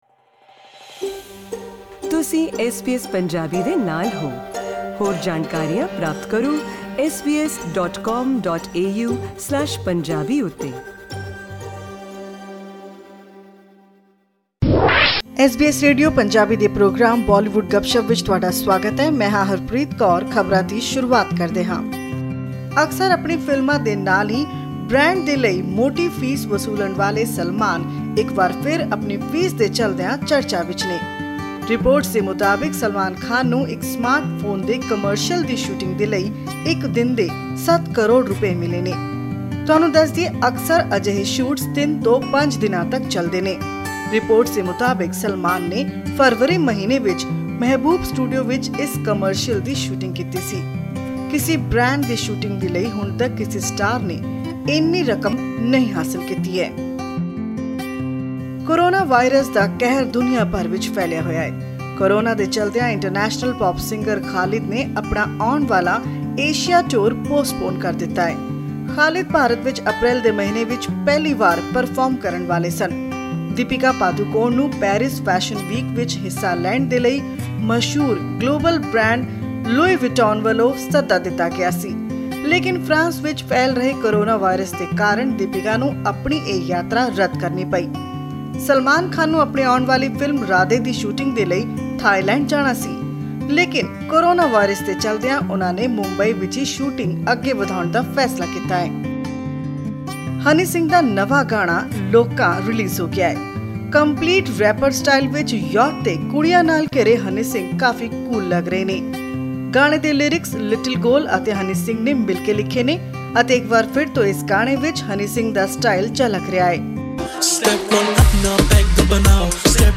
Click on the audio to listen to the full report.